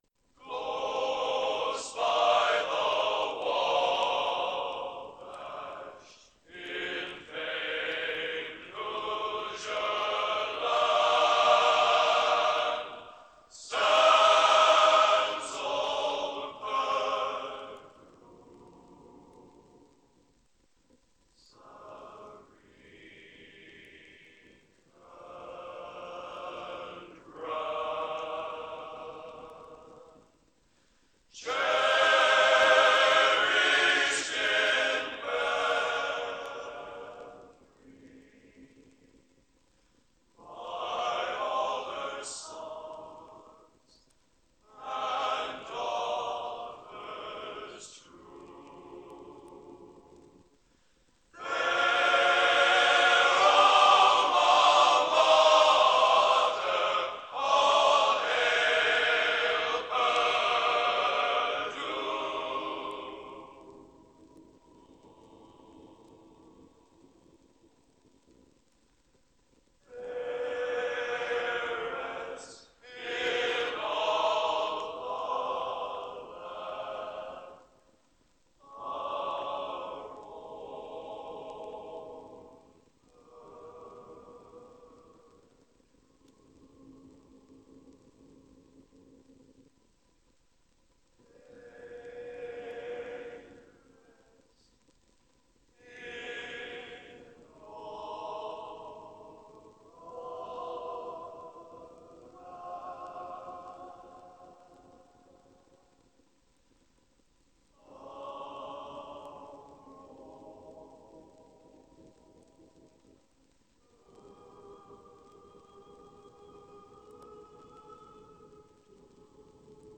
Genre: Collegiate | Type: Studio Recording